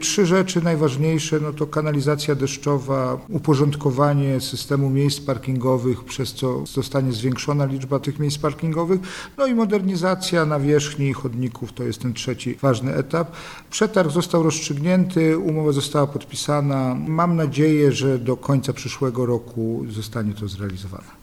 – Osiedle powstało 50 lat temu i od tej pory infrastruktura nie była remontowana – powiedział Jacek Milewski, prezydent Nowej Soli: